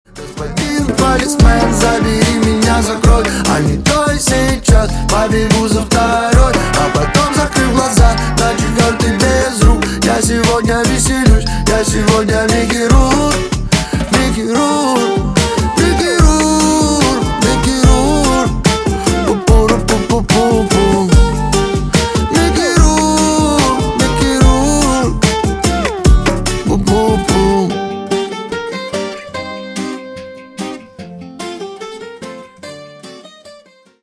• Качество: 320, Stereo
гитара
веселые